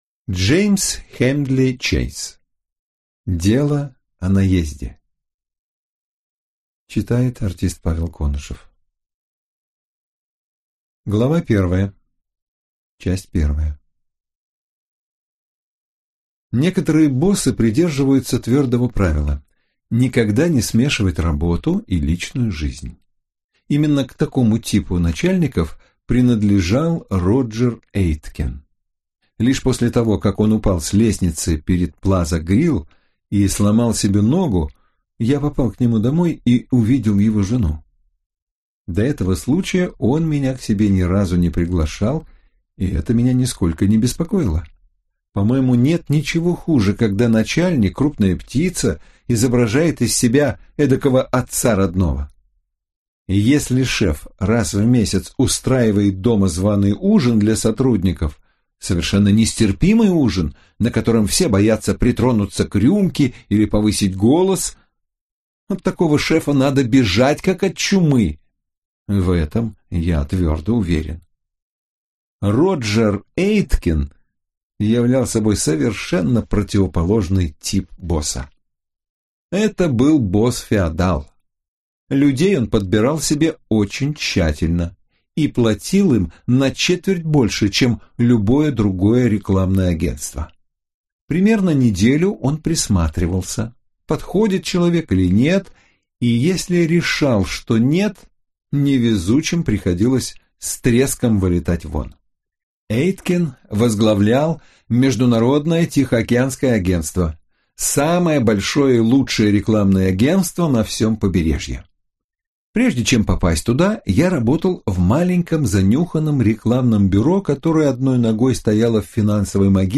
Аудиокнига Дело о наезде | Библиотека аудиокниг